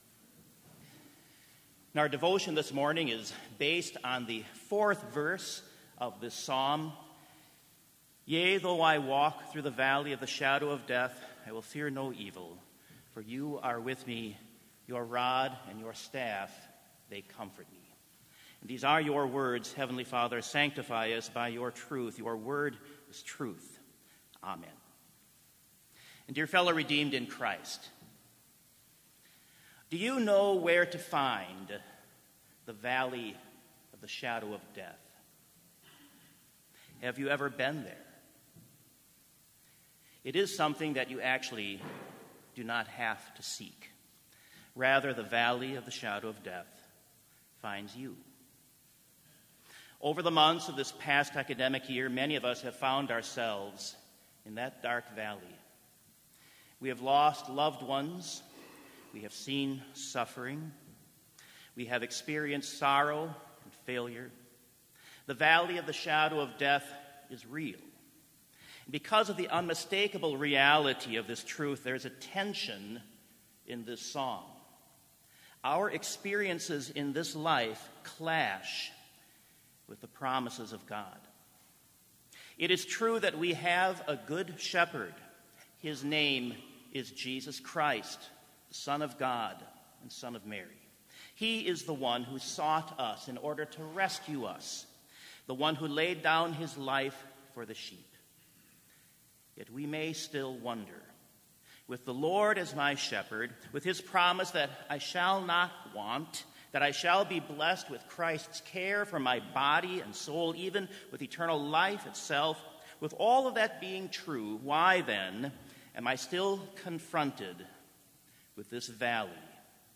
Complete Service
This Chapel Service was held in Trinity Chapel at Bethany Lutheran College on Wednesday, May 8, 2019, at 10 a.m. Page and hymn numbers are from the Evangelical Lutheran Hymnary.